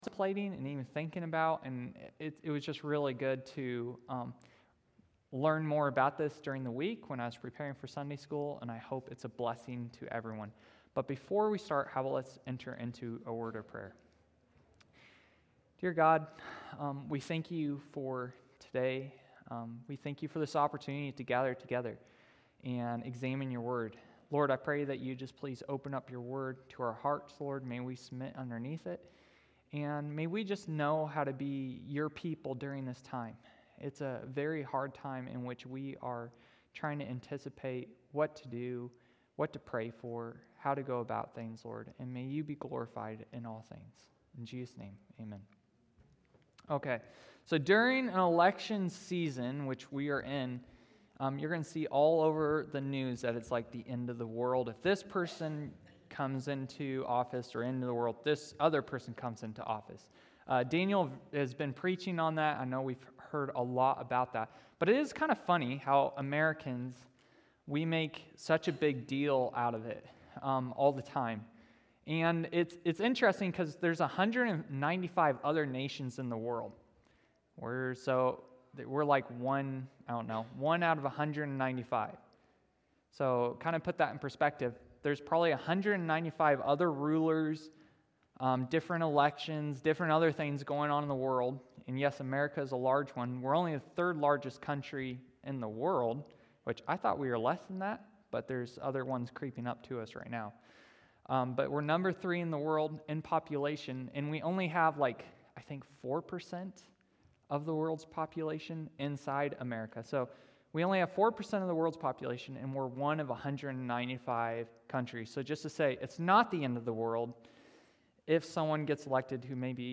Adult Sunday School 10/27/24